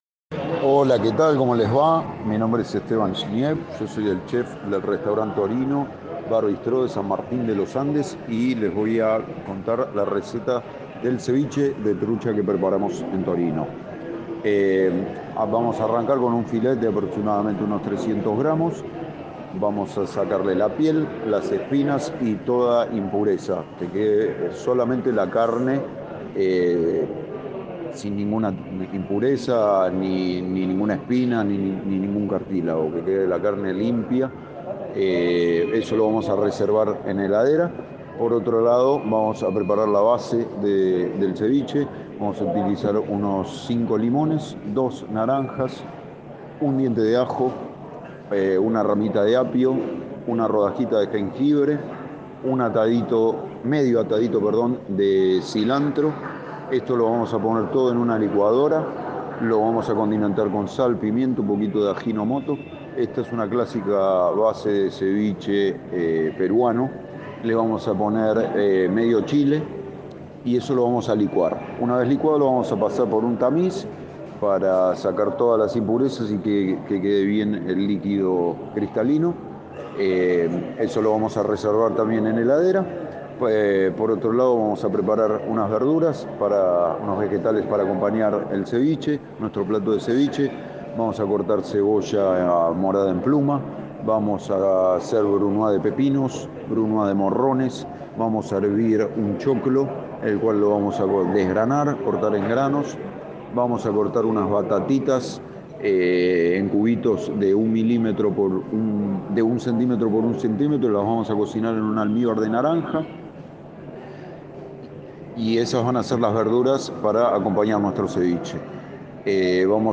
Audio receta del ceviche de trucha